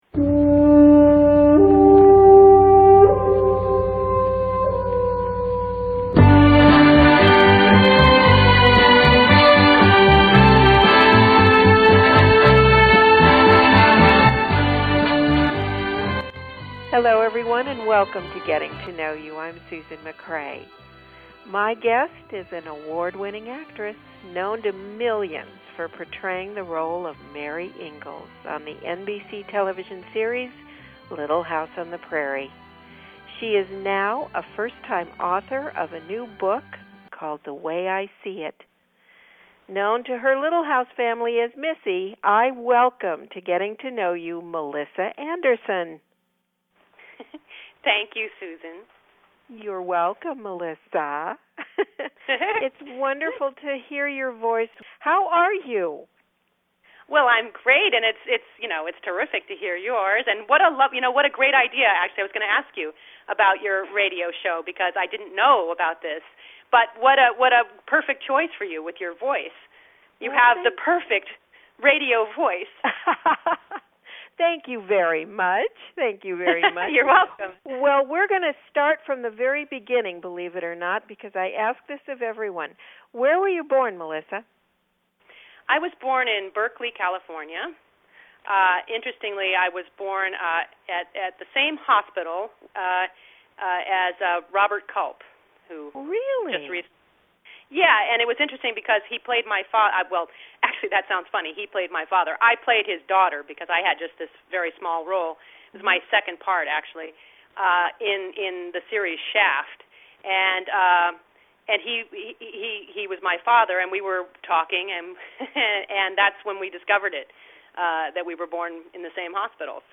New audio interview